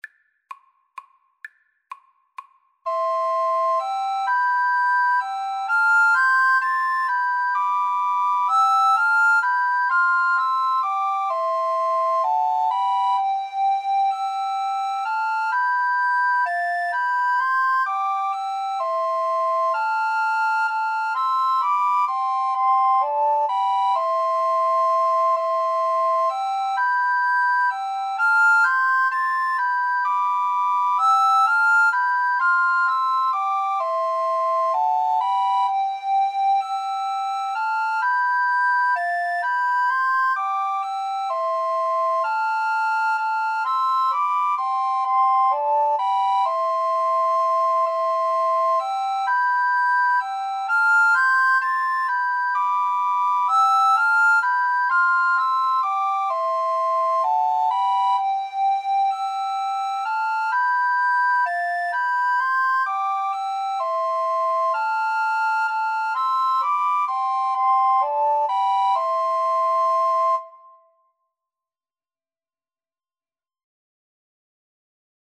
Soprano RecorderAlto RecorderTenor Recorder
C major (Sounding Pitch) (View more C major Music for Recorder Trio )
3/4 (View more 3/4 Music)
quem_pastores_SATRC_kar3.mp3